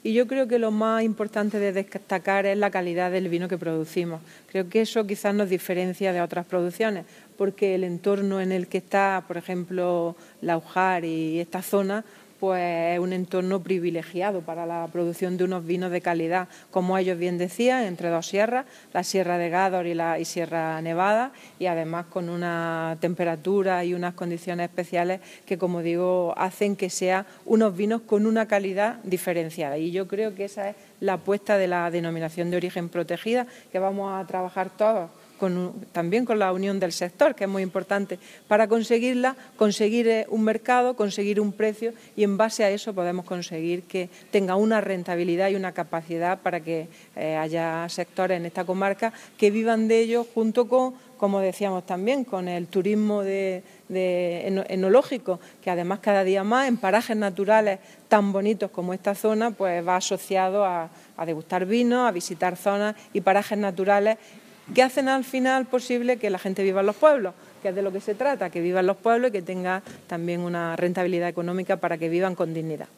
Declaraciones de Carmen Ortiz sobre vino de calidad diferenciada